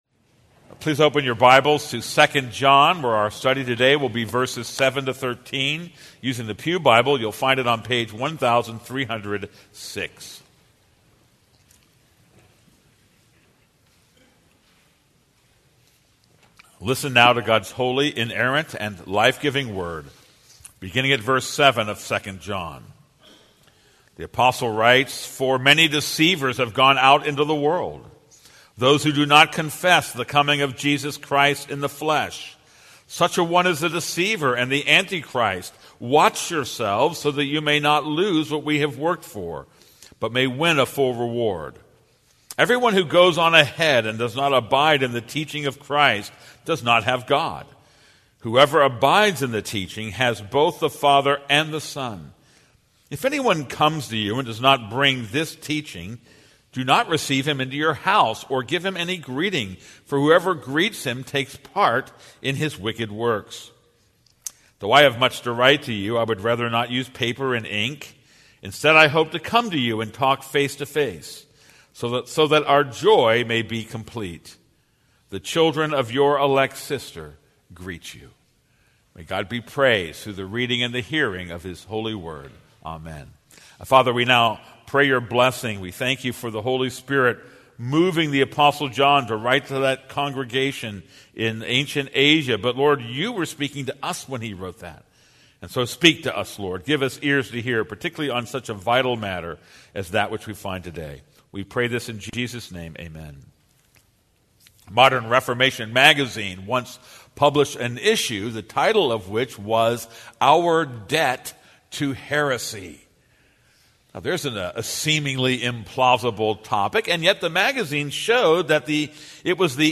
This is a sermon on 2 John 7-13.